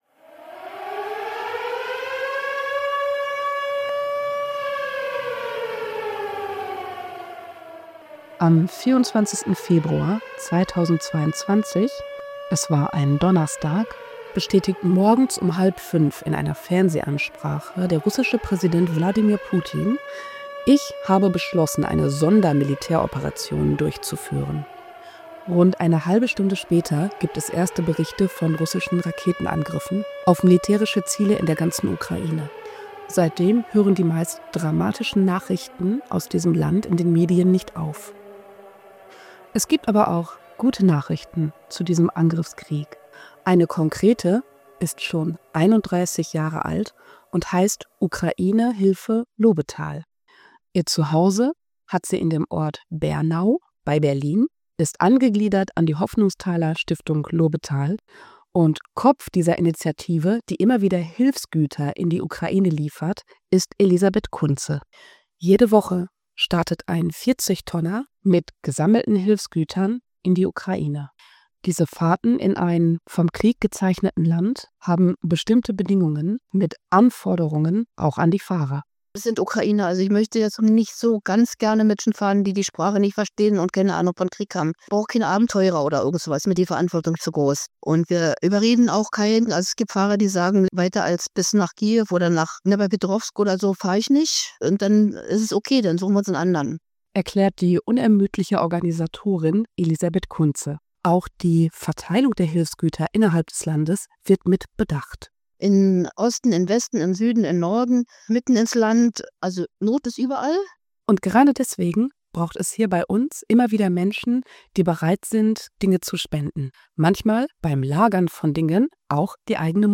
Zum Jahrestag des Ukraine-Kriegs ein Bericht über die Ukraine-Hilfe Lobetal